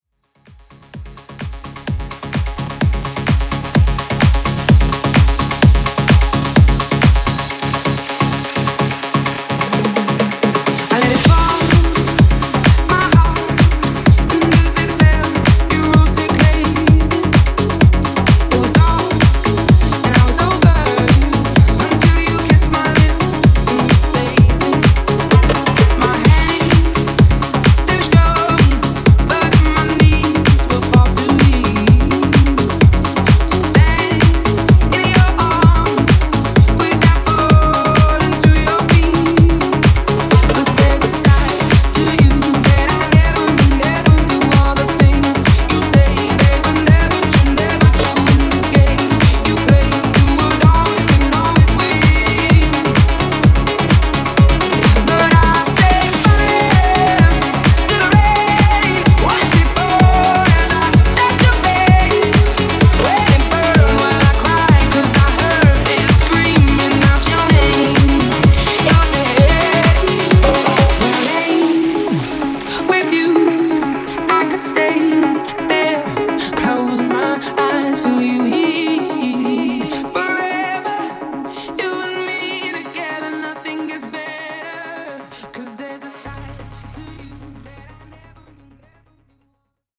Genre: 70's
Clean BPM: 128 Time